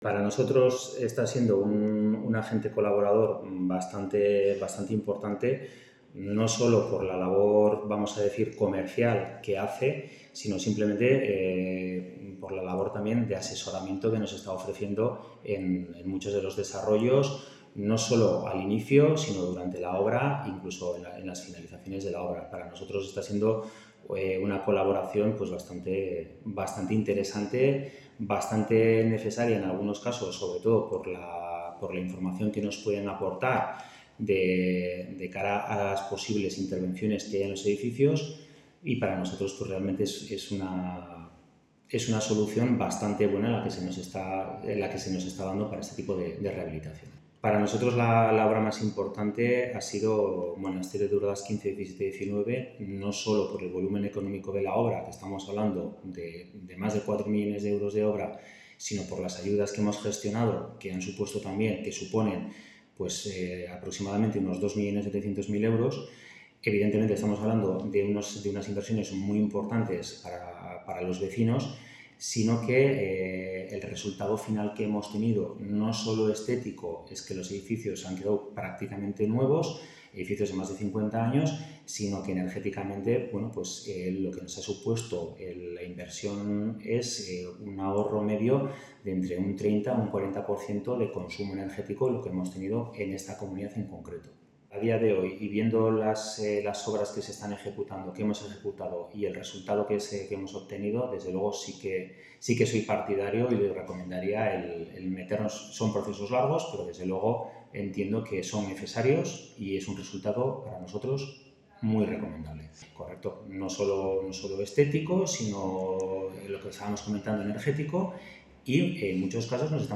Vecindario que ha rehabilitado sus viviendas con ayudas del Gobierno de Navarra, administradores de fincas y arquitectos explican su experiencia en la reforma de los edificios que reporta hasta un 40% de ahorros energéticos y baja 4 grados la temperatura en verano